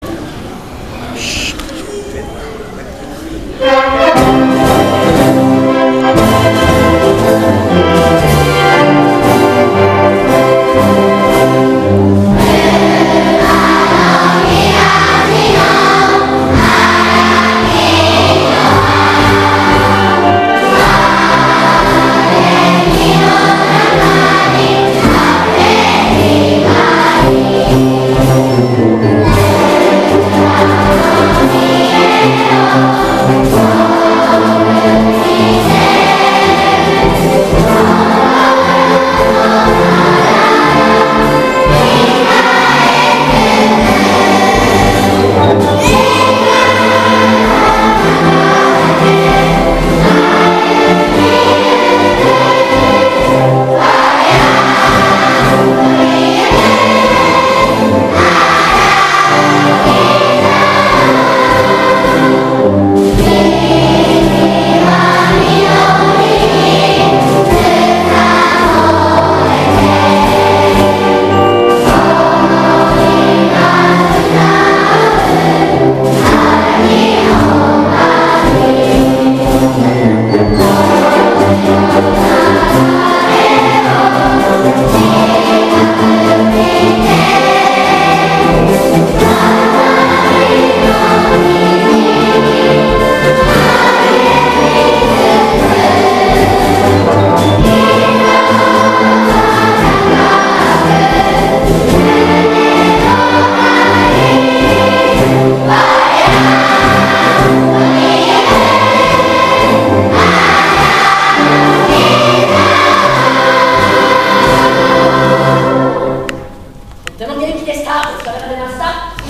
校歌斉唱.mp3